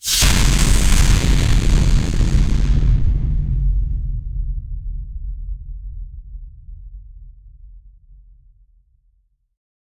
Faca um som de rajada de energia cosmica como da capitã marvel forte
faca-um-som-de-rajada-zvfbroix.wav